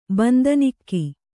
♪ bandanikki